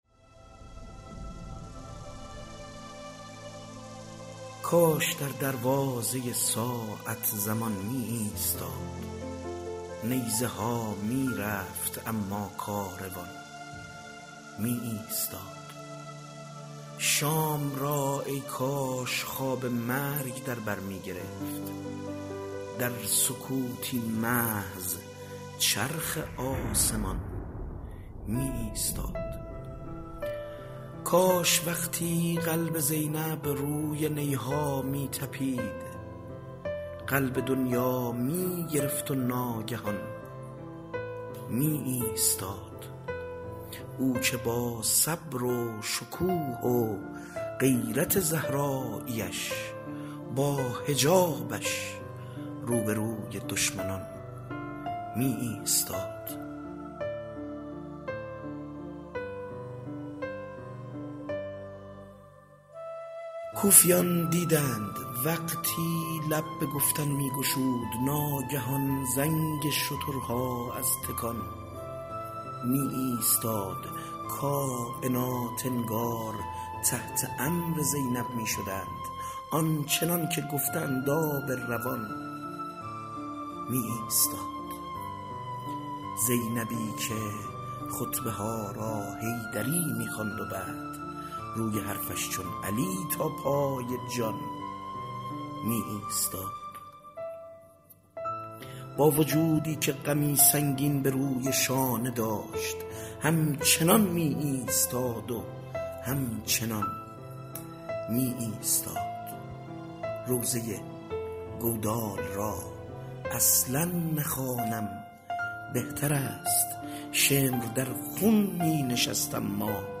بسته صوتی طریق اشک/شعرخوانی شعرای آیینی ویژه اربعین
سرویس شعر آیینی عقیق : بسته صوتی طریق اشک صوت شعر خوانی تعدادی از شاعران اهل بیت است  که به همت استودیو همنوا و با حمایت خانه ی موسیقی بسیج  تهیه شده که در ایام اربعین از رادیو اربعین پخش خواهد شد.